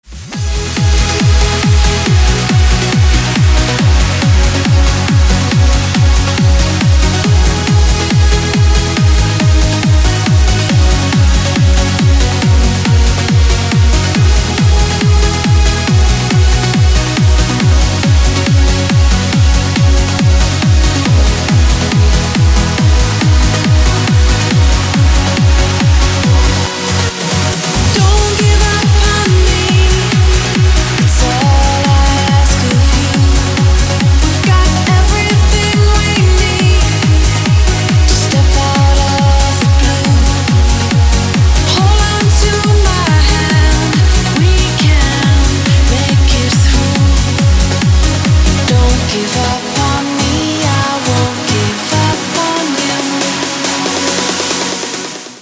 • Качество: 256, Stereo
громкие
женский вокал
dance
Electronic
электронная музыка
club
Trance